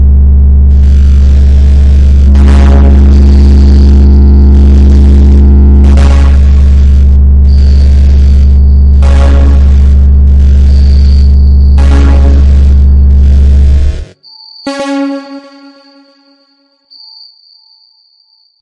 鼓 贝斯 吉他 " 贝斯合成器
描述：由FL工作室创作的低音合成音